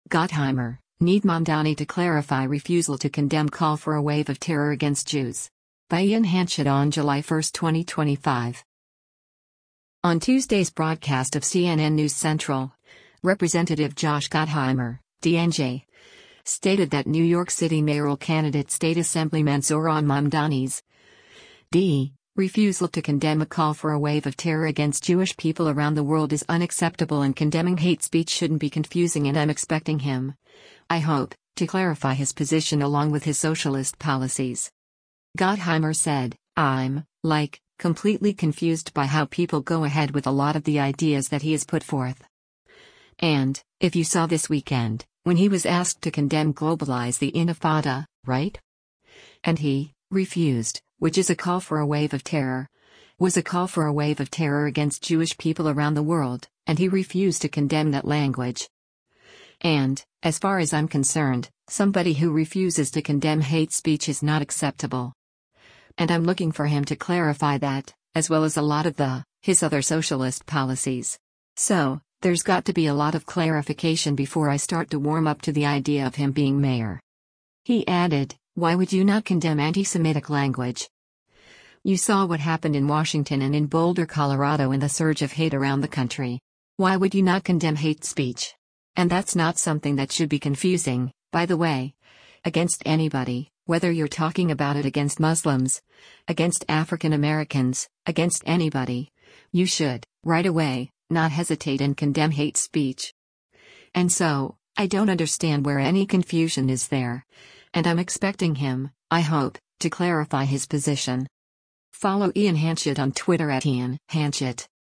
On Tuesday’s broadcast of “CNN News Central,” Rep. Josh Gottheimer (D-NJ) stated that New York City mayoral candidate State Assemblyman Zohran Mamdani’s (D) refusal to condemn “a call for a wave of terror against Jewish people around the world” is unacceptable and condemning hate speech shouldn’t be confusing “and I’m expecting him, I hope, to clarify his position” along with his “socialist policies.”